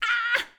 SFX_Battle_Vesna_Defeated_01.wav